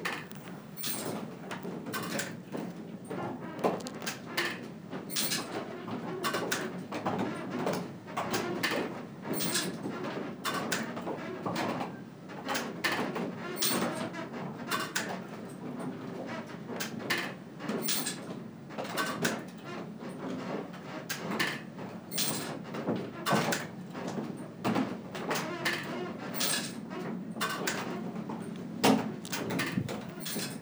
windmillInside.wav